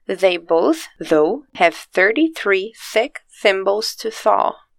Mas não se preocupe: os áudios para que você escute as pronúncias corretas e pratique todos eles estão presentes para te ajudar, como sempre!
O próximo trava-línguas tem a maior parte dos sons de “th” no começo das palavras, exceto por uma: “both”.